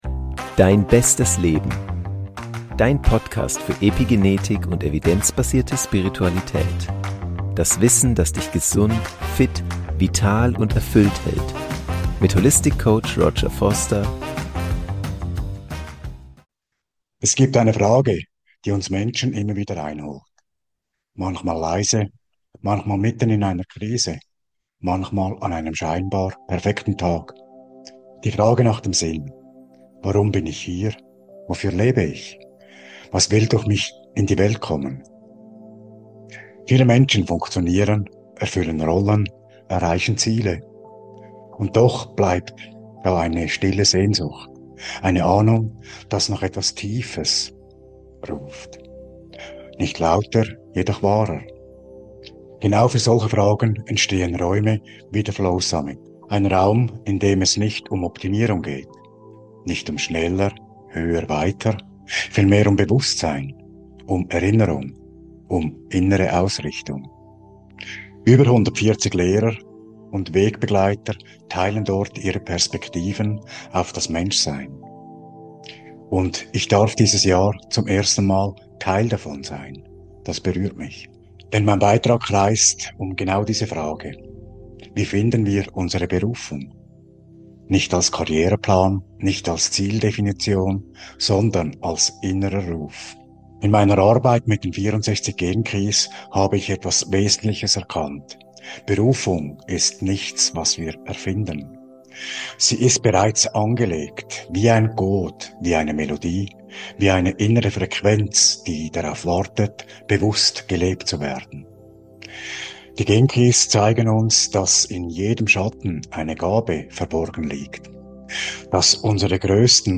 Die Meditation im Video ermöglicht es Dir, in eine tiefe Verbindung mit Dir selbst zu gelangen und Deine Berufung wieder zu spüren.